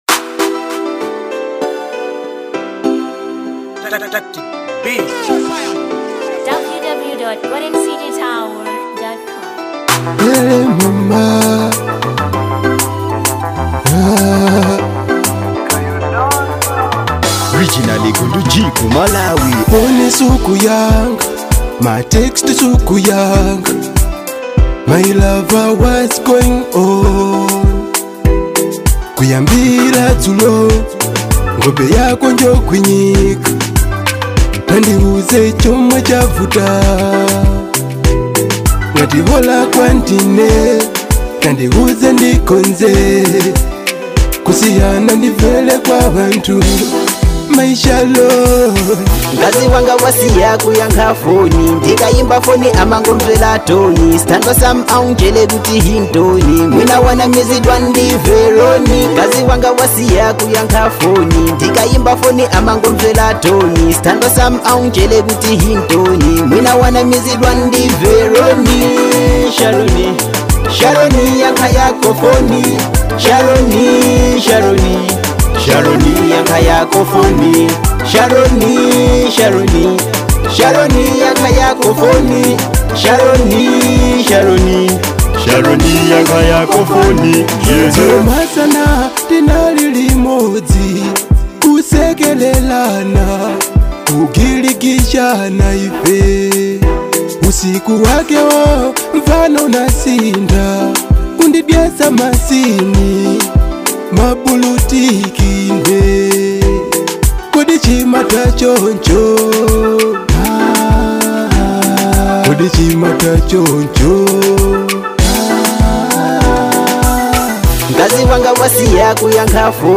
Afro Pop